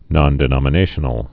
(nŏndĭ-nŏmə-nāshə-nəl)